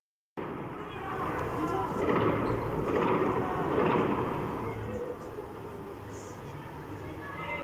بهاران سنندج الان